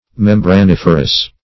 membraniferous - definition of membraniferous - synonyms, pronunciation, spelling from Free Dictionary
Search Result for " membraniferous" : The Collaborative International Dictionary of English v.0.48: Membraniferous \Mem`bra*nif"er*ous\, a. [Membrane + -ferous.] Having or producing membranes.